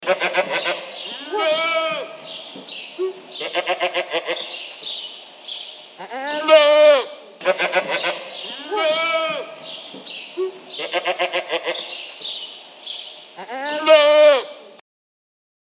Download Farm sound effect for free.
Farm